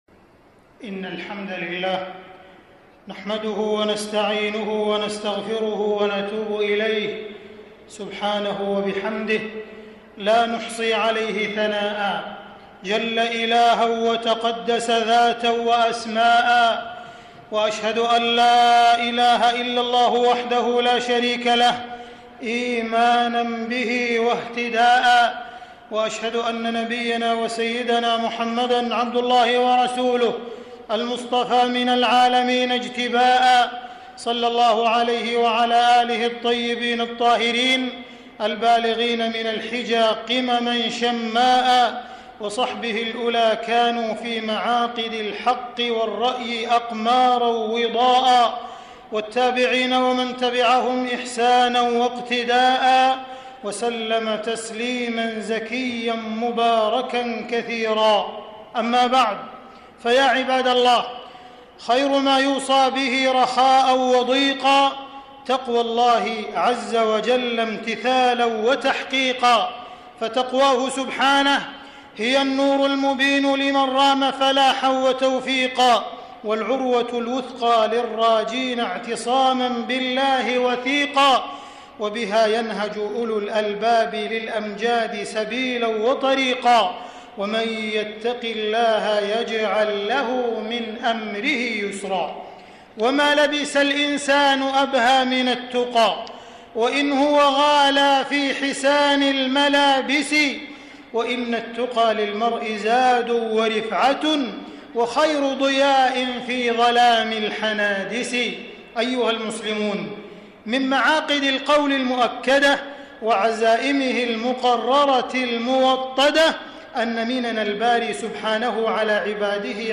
تاريخ النشر ٣ صفر ١٤٣٥ هـ المكان: المسجد الحرام الشيخ: معالي الشيخ أ.د. عبدالرحمن بن عبدالعزيز السديس معالي الشيخ أ.د. عبدالرحمن بن عبدالعزيز السديس نعمة العقل والإدراك